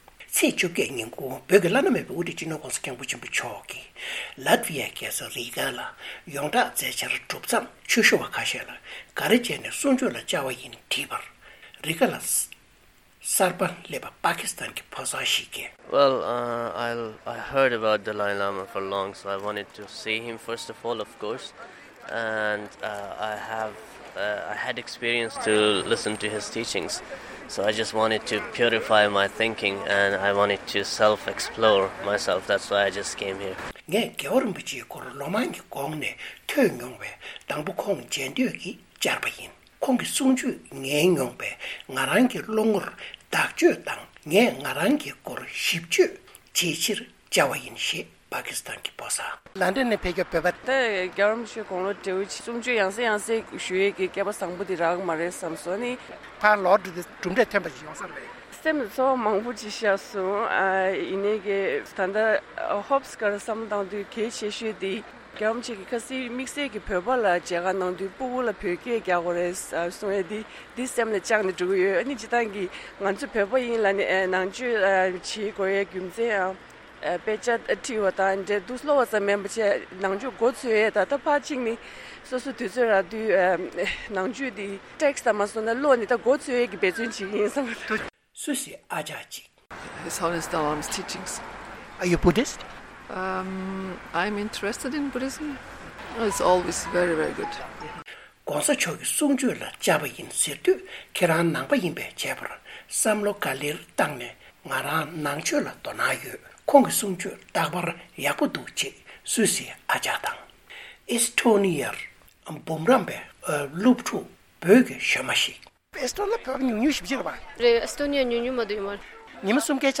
སྒྲ་ལྡན་གསར་འགྱུར།
སྤྱི་ནོར་༸གོང་ས་༸སྐྱབས་མགོན་ཆེན་པོ་མཆོག་གིས་ལཏ་ཝི་ཡའི་རྒྱལ་ས་རི་གྷ་ནང་བསྩལ་བའི་ཆོས་འབྲེལ་སོགས་ཀྱི་མཛད་འཆར་གྲུབ་རྗེས།    ཆོས་ཞུ་བ་ཁག་ཅིག་ལ་བཅར་འདྲི་ཞུས་པ་ཞིག་